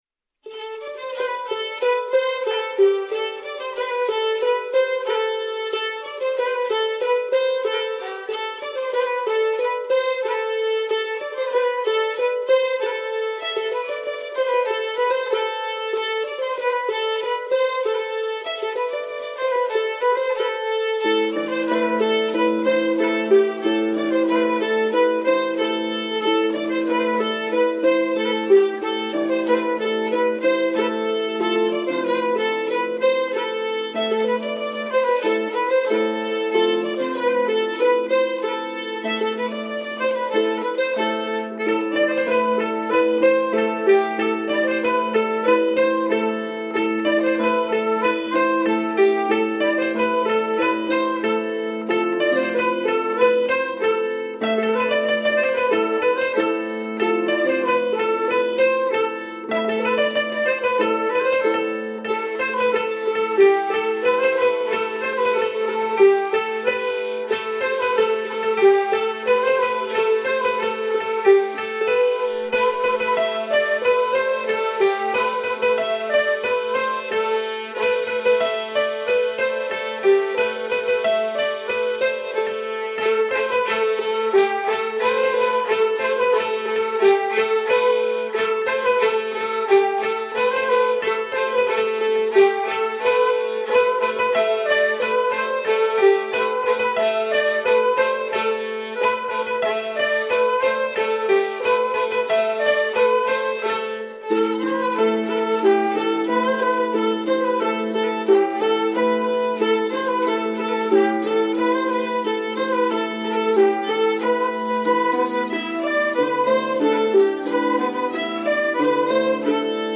Les enregistrement de groupes d’élèves ont été effectués à la Carène, en avril 2016.
Un kas a barh, joué par les Ecoliers de Landerneau, l’atelier des jeunes.